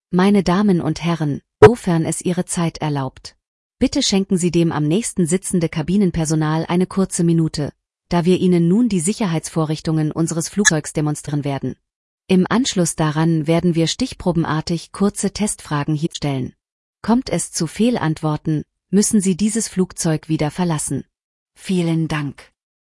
PreSafetyBriefing.ogg